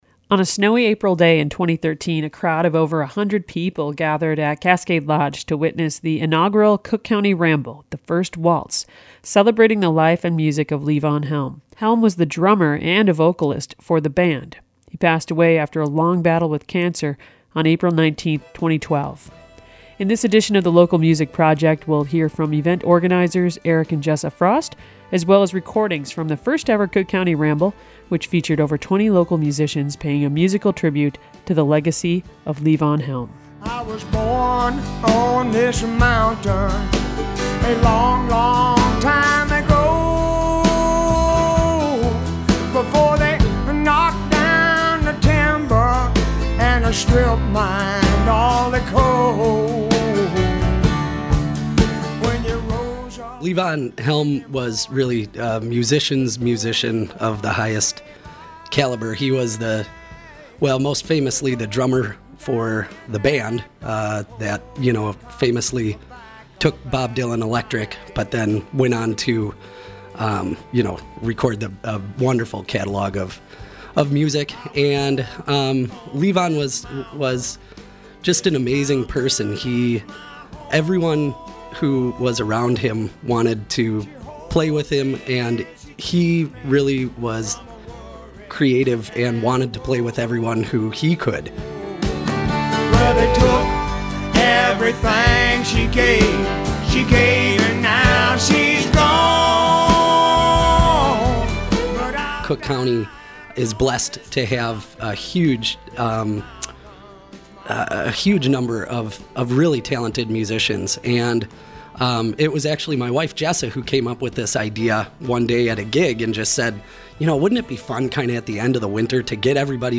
Attachment Size LMP_Cook County Ramble_FINAL.mp3 14.45 MB On a snowy April night, a crowd of about one hundred gathered at the Cascade Lodge and Pub in Lutsen to hear local musicians pay tribute to the late drummer and vocalist for The Band, Levon Helm.